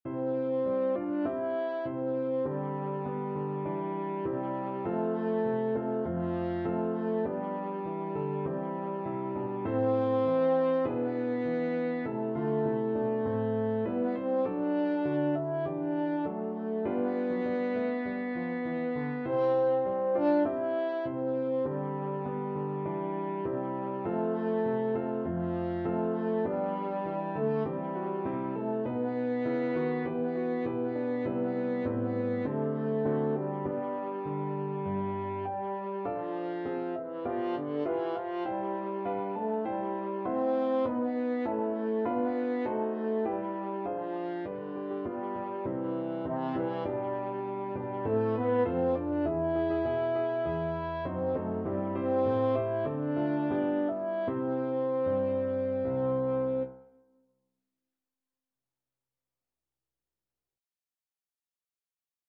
Free Sheet music for French Horn
French Horn
C major (Sounding Pitch) G major (French Horn in F) (View more C major Music for French Horn )
4/4 (View more 4/4 Music)
Traditional (View more Traditional French Horn Music)